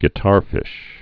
(gĭ-tärfĭsh)